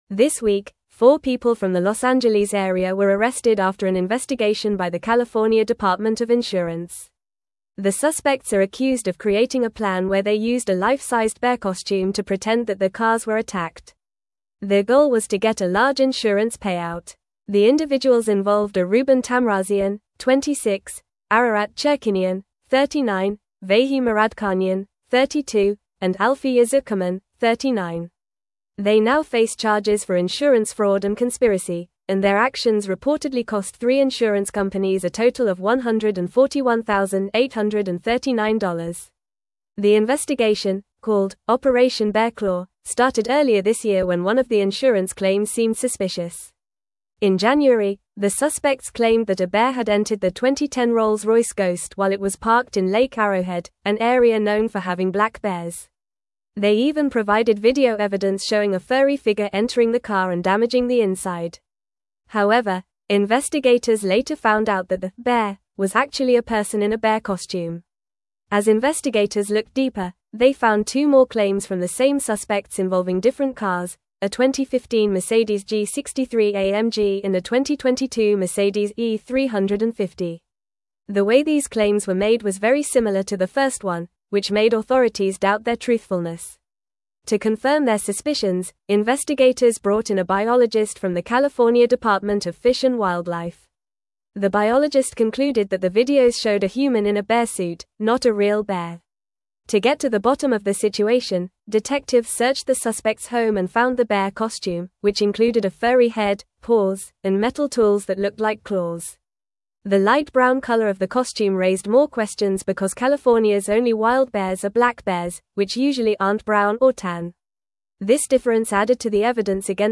Fast
English-Newsroom-Upper-Intermediate-FAST-Reading-Suspects-Arrested-for-Staging-Bear-Attack-Insurance-Fraud.mp3